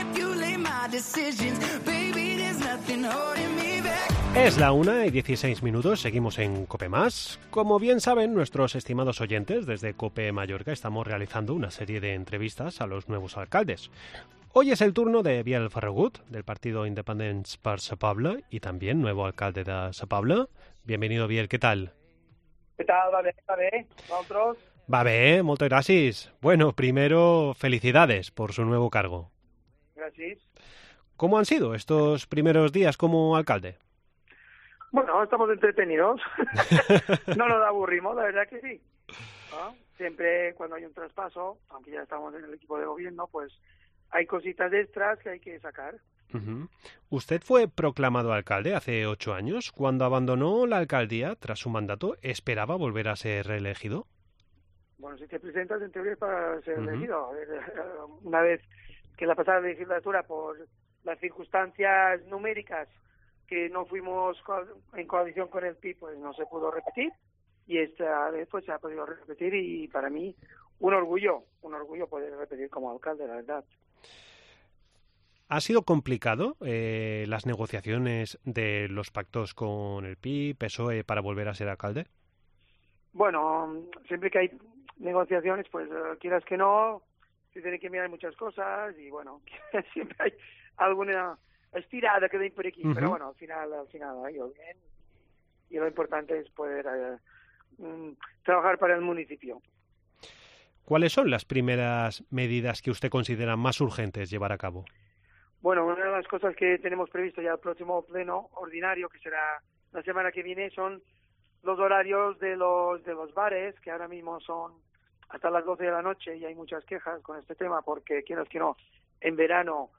AUDIO: En nuestra ronda de entrevistas con los nuevos alcaldes, hoy es el turno de Biel Ferragut del partido Independets per Sa Pobla y nuevo alcalde...